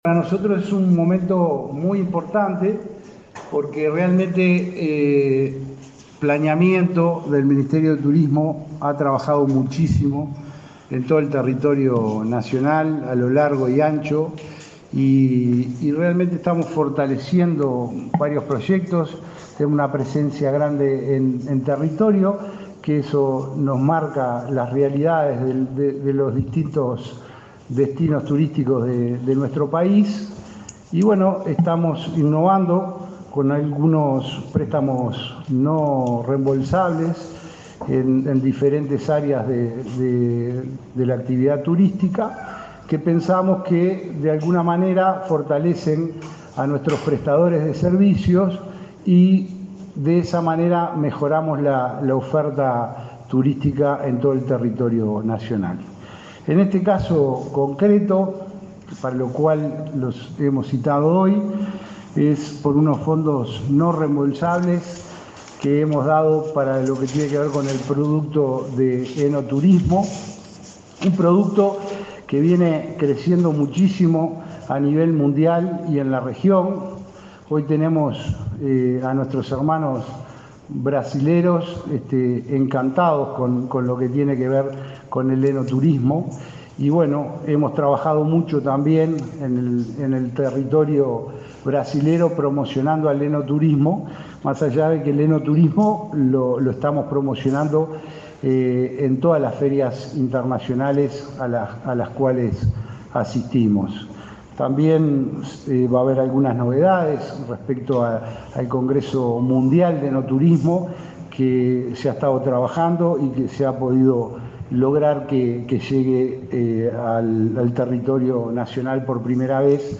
Palabras de autoridades del Ministerio de Turismo
Este miércoles 21, el director nacional de Turismo, Roque Baudean, y el ministro Tabaré Viera participaron en el acto de premiación de los